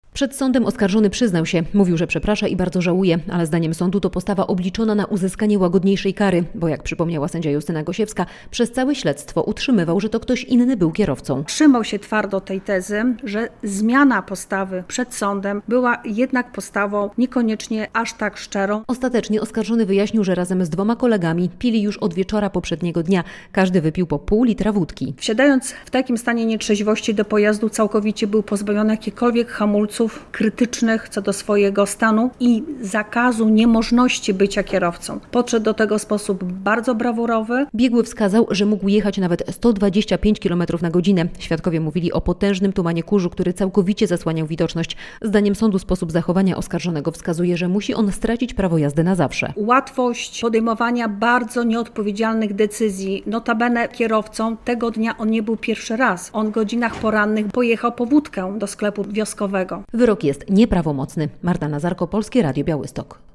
Wyrok za śmiertelny wypadek - relacja